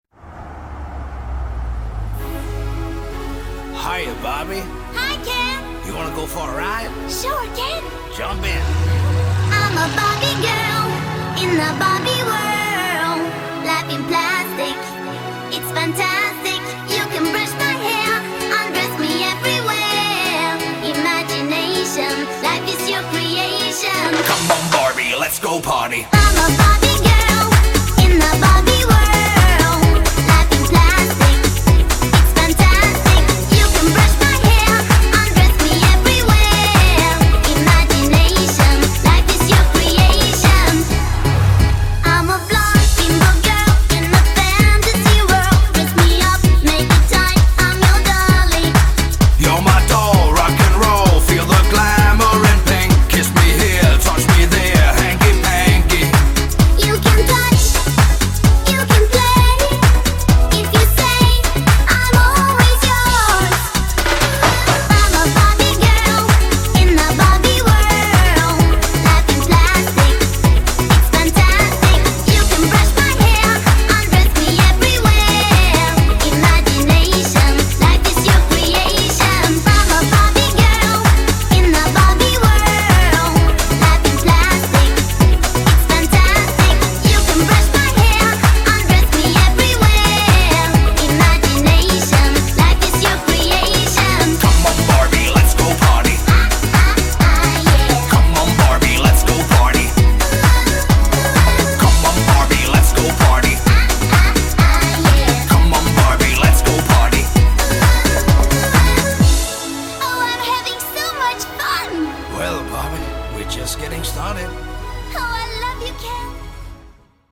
BPM130
Audio QualityLine Out